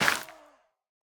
Minecraft Version Minecraft Version snapshot Latest Release | Latest Snapshot snapshot / assets / minecraft / sounds / block / soul_soil / break1.ogg Compare With Compare With Latest Release | Latest Snapshot